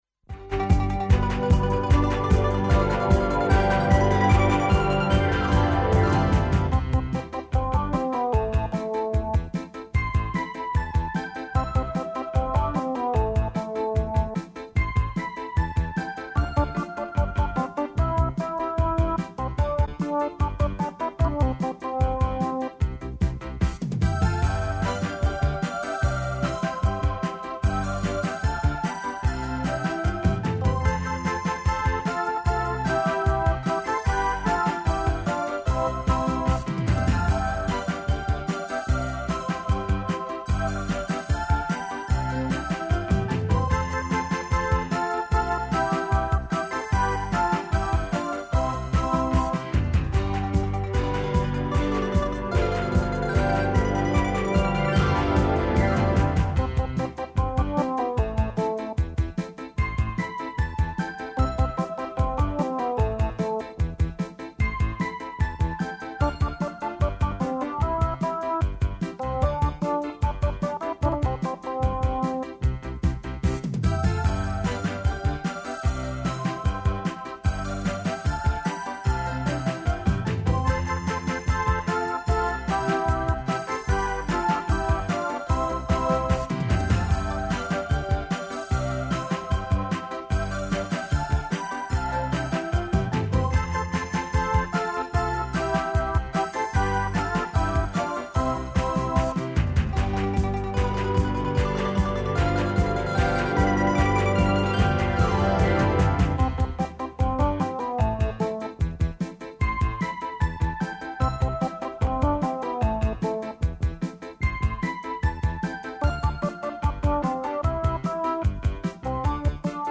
Посетићемо камп у Буковичкој бањи и разговарати са Јеленом Генчић.